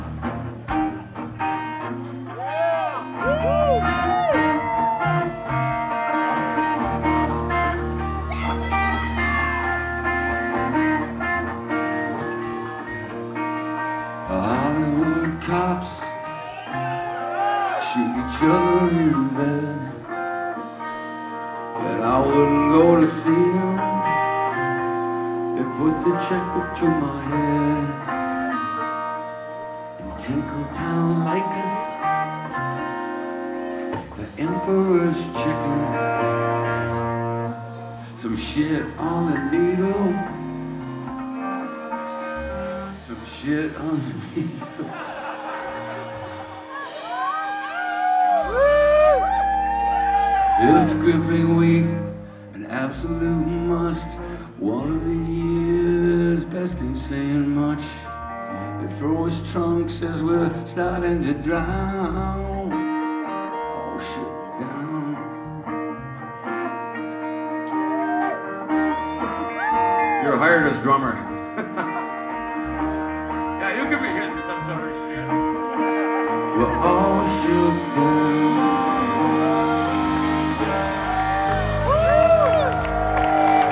Live at the Sinclair, Cambridge, MA